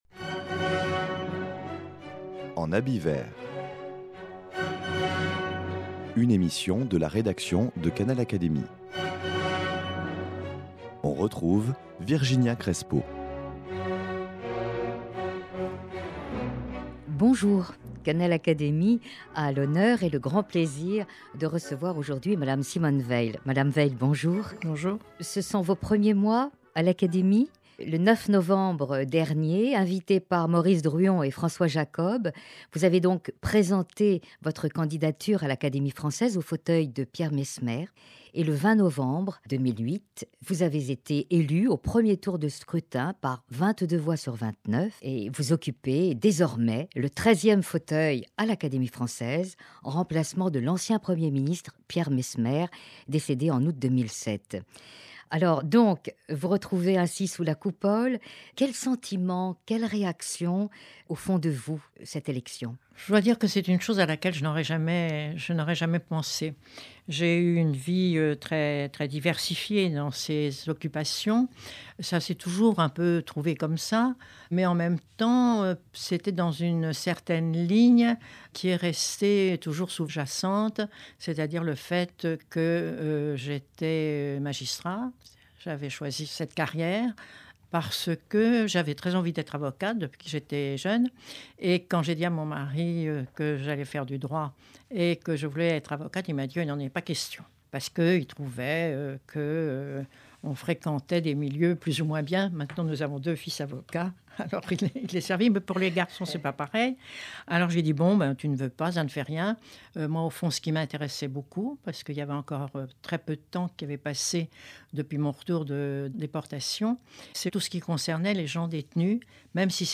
Simone Veil, avec ses mots, ses émotions, confie dans ce livre et devant les micros de Canal Académie: une jeunesse-témoin de la souffrance, de la barbarie, de l’indicible de la déportation – puis avec la libération et le retour à la vie: le bonheur de créer une famille, avec la joie renouvelée trois fois de donner la vie – ensuite une entrée dans la vie professionnelle en qualité de Magistrat puis dans la vie politique, pour défendre les droits des « oubliés » de nos sociétés, telles sont les bases « du destin exceptionnel de cette femme politique dont la légitimité est la moins contestée en